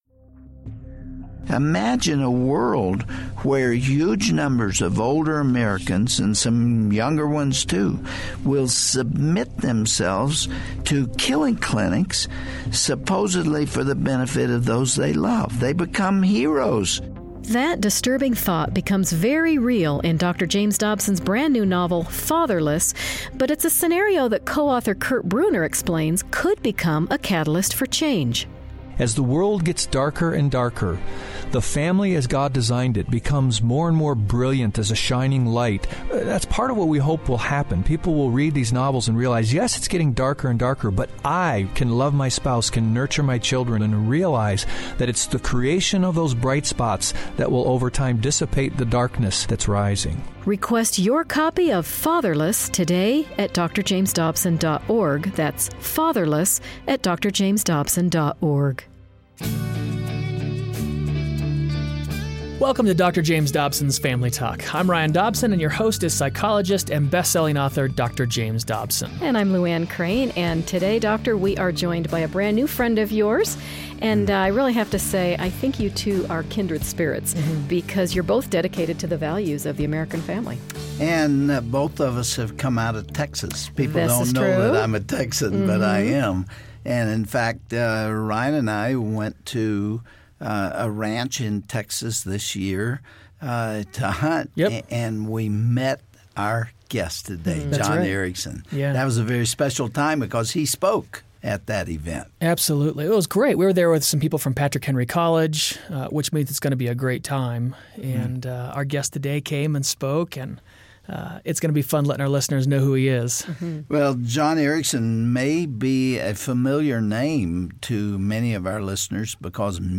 We talk with a man committed to creating wholesome and uplifting entertainment for kids. He's a true cowboy who stuck to his vision to write children's books that teach the life lessons he learned on the farm.